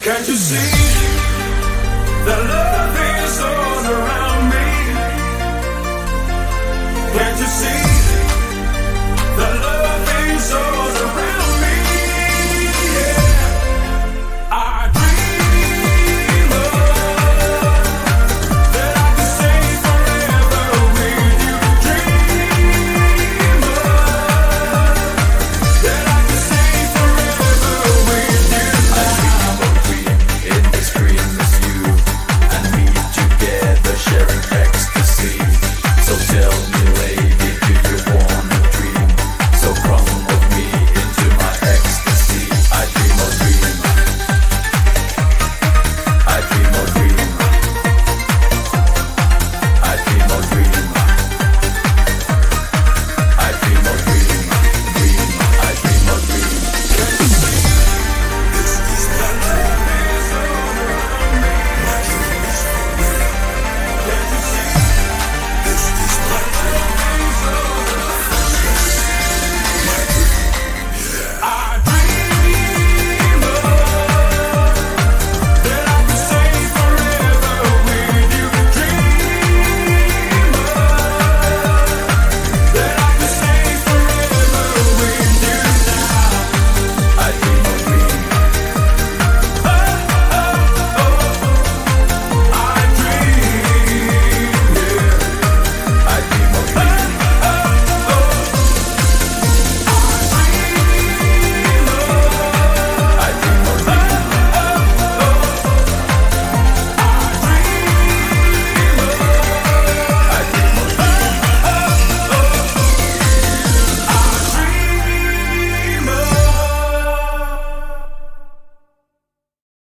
BPM135
Audio QualityPerfect (High Quality)
Comments* The real BPM of this song is 134.95
well-known for their eurodance music in the late 90s.